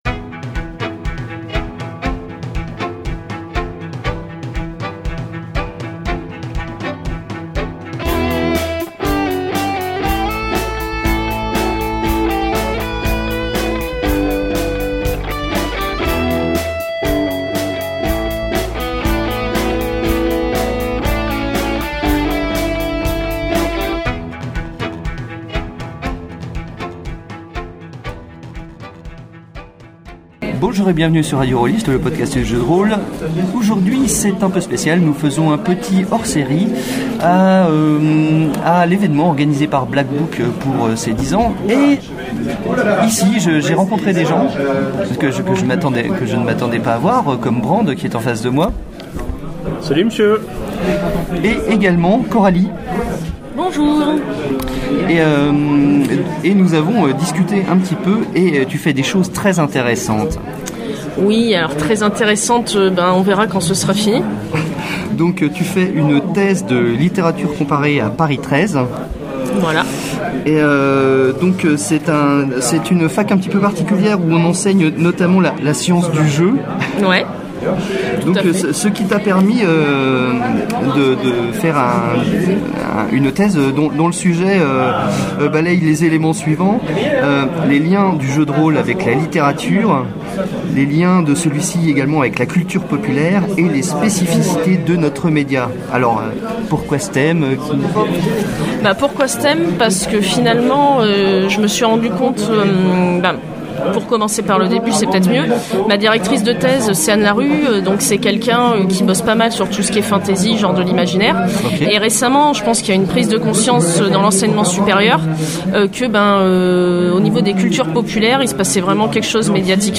Mi-avril je me trouvais dans la région lyonnaise à l’événement organisé par Black Book pour ses dix ans
Et j’ai ramené une petite interview.